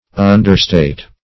Understate \Un`der*state"\, v. t.